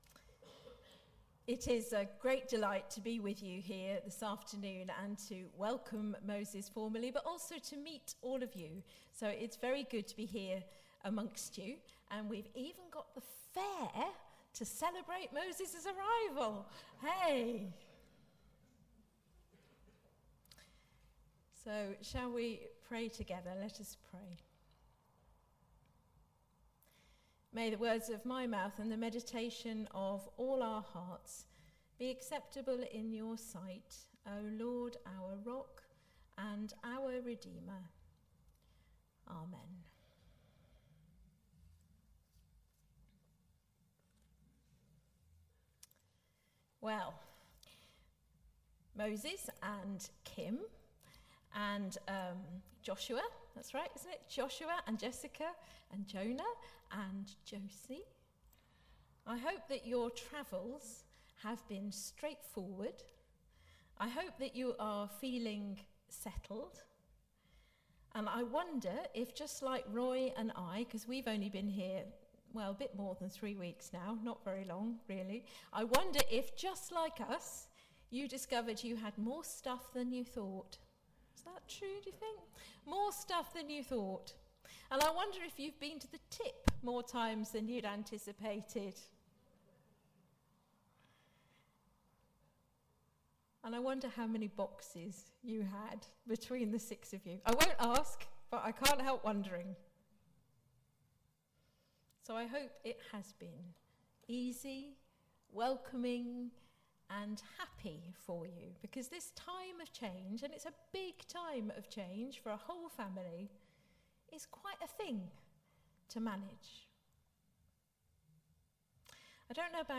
Passage: Ruth 1:1-19a, Mark 16:1-7 Service Type: Celebration
08-31-sermon.mp3